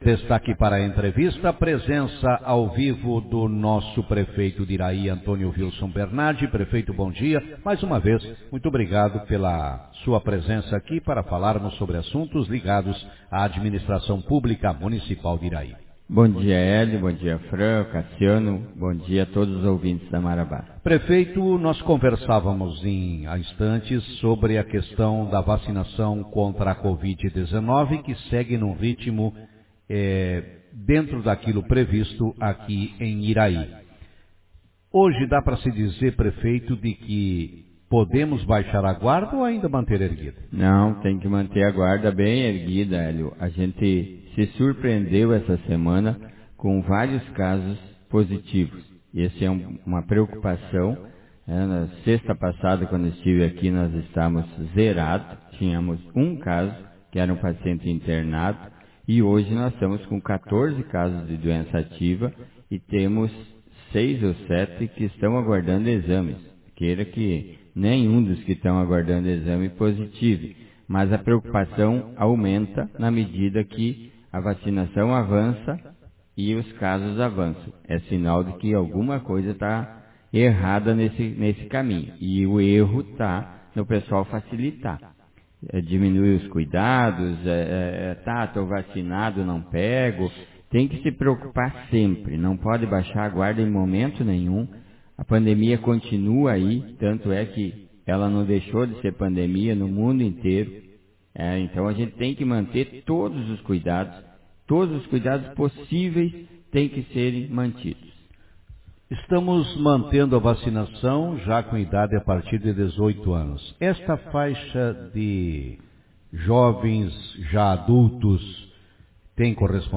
Prefeito alerta a população com relação ao aumento de casos positivados de Covid em Iraí Autor: Rádio Marabá 27/08/2021 0 Comentários Manchete Na manhã de hoje no programa Café com Notícias, o Prefeito Antonio Vilson Berbardi, alertou a população com relação ao aumento considerável de casos de pessoas positivadas de coronavírus.